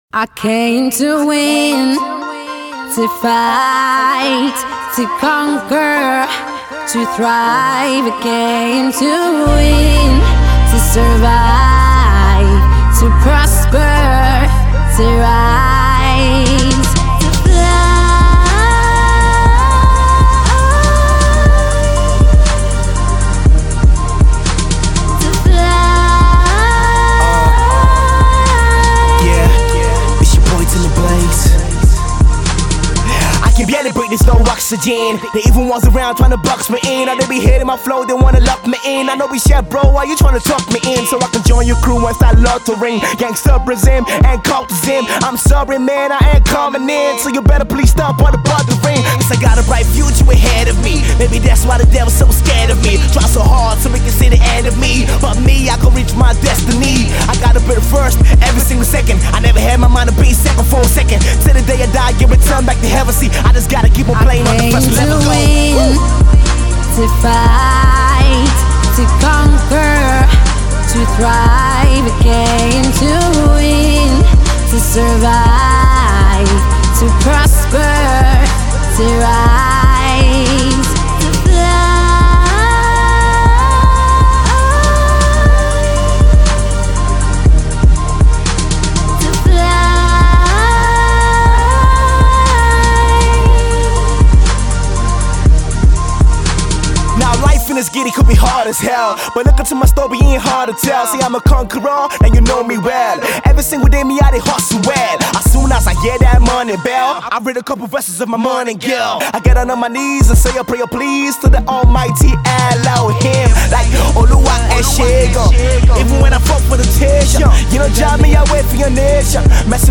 Hip-Hop
Female Singer